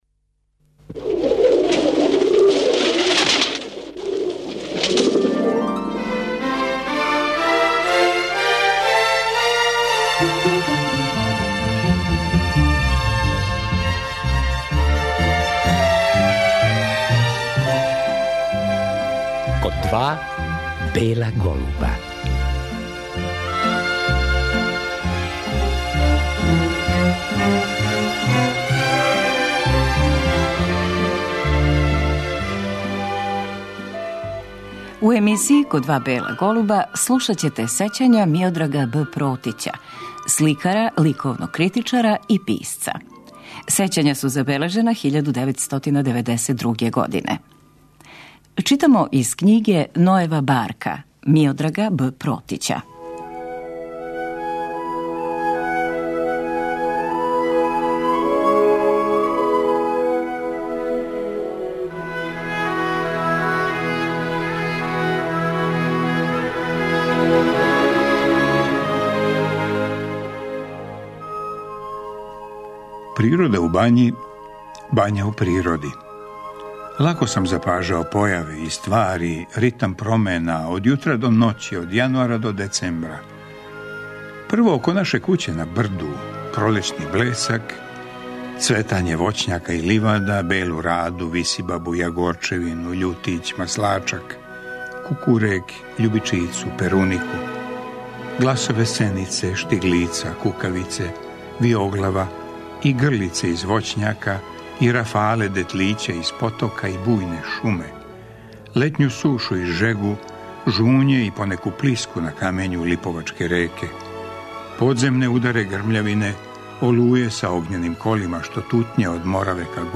Сликар Миодраг Б. Протић, оснивач и први директор Музеја савремене уметности у Београду, теоретичар, историчар и критичар југословенске и српске модерне и савремене уметности, био је гост ове емисије у јануару 1993. године поводом изласка књиге 'Нојева барка' – Поглед с краја века (1900-1965)